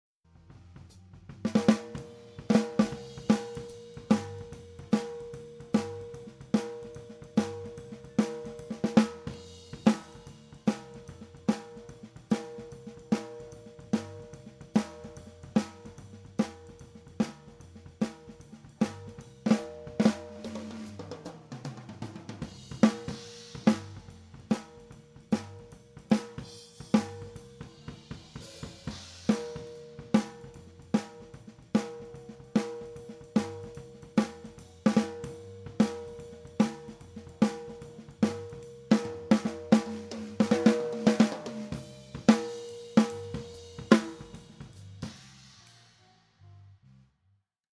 Live-Mitschnitt bei Kneipengig - Bericht mit Audiobeispielen
SN (SM57)
Hier die Soundbeispiele - die Einzelsignale sind roh und unbearbeitet - die Mischung natürlich schon...
Snaredrum
Snare_SM57.MP3